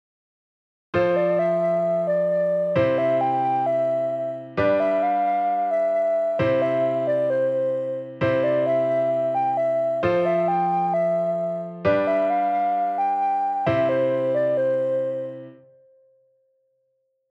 先ほどのサンプルを2回繰り返すとして、1回目と2回目で音形を変えて対比させてみます。
1小節目の下がっていたところを上げて、4小節目の上がっていたところを下げてみました。